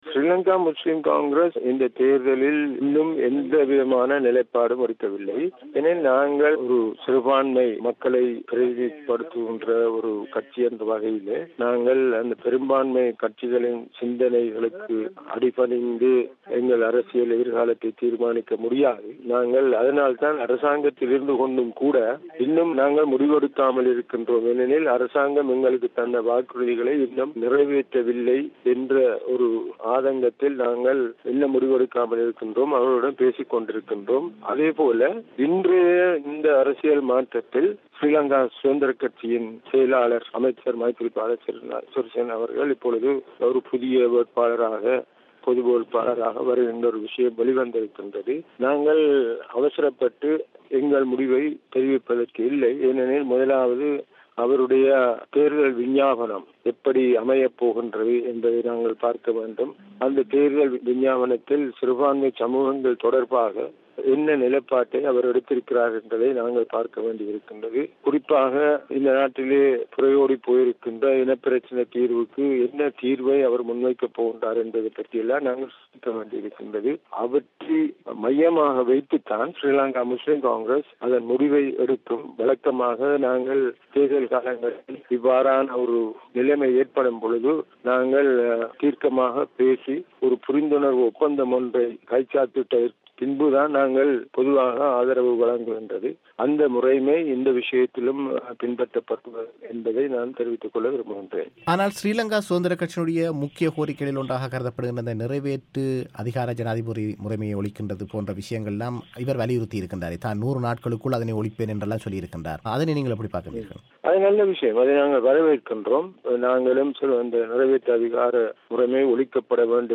அவர் பிபிசிக்கு வழங்கிய செவ்வியை இங்கு கேட்கலாம்.